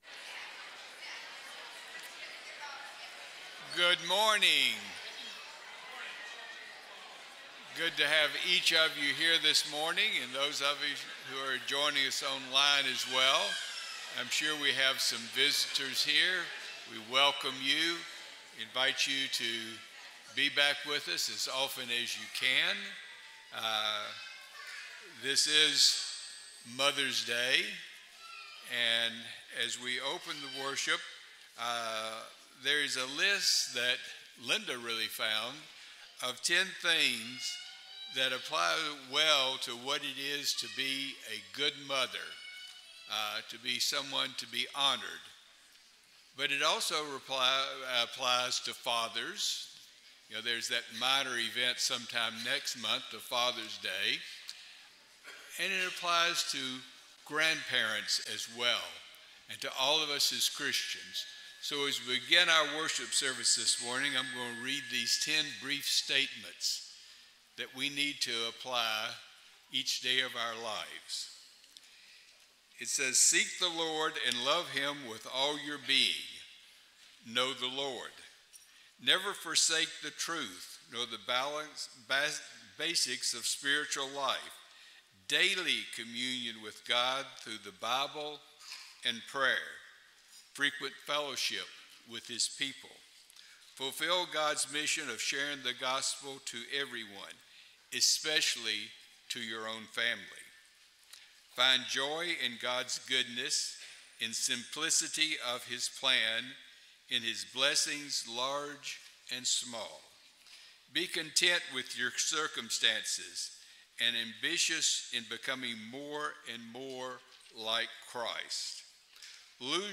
Proverbs 31:28, English Standard Version Series: Sunday AM Service